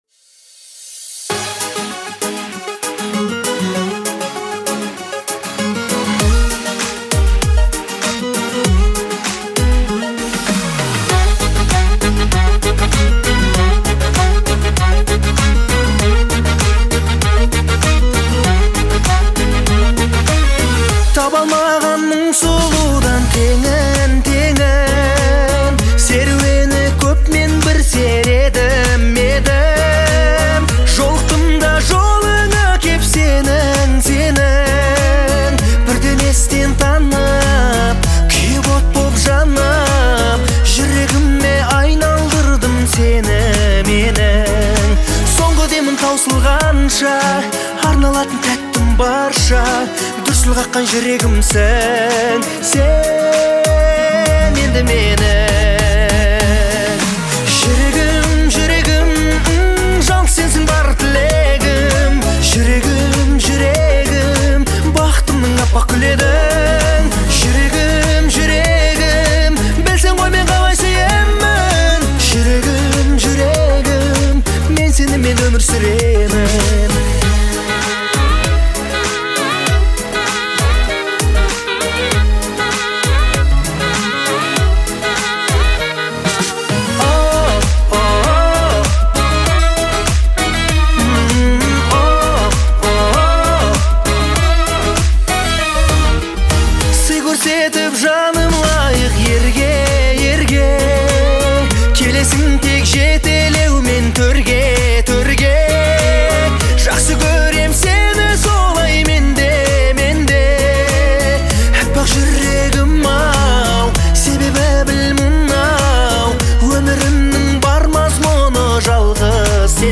это трогательная песня в жанре поп